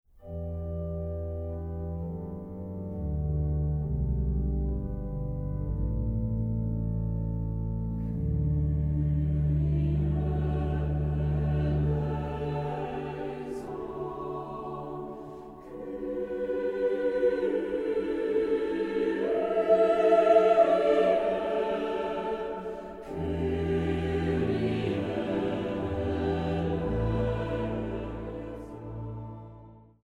Orgel
Die Aufnahmen fanden in der Kreuzkirche in Dresden statt.